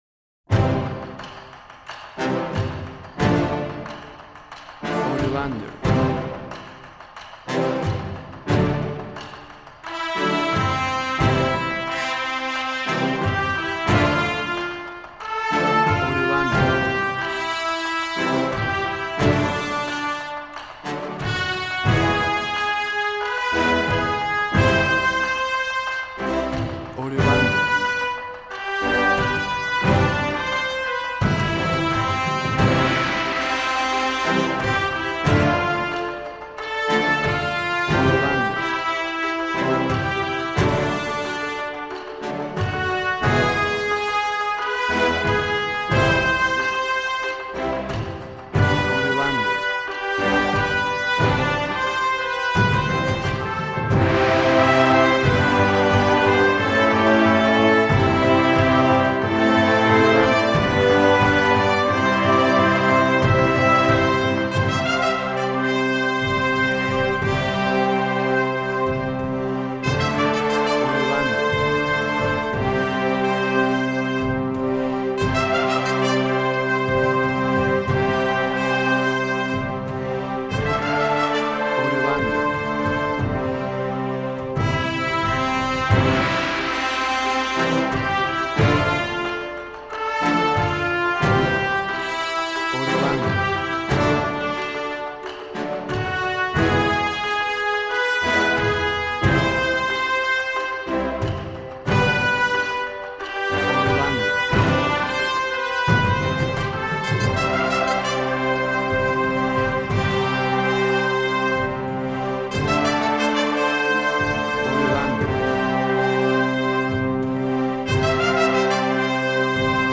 Action and Fantasy music for an epic dramatic world!
Tempo (BPM): 90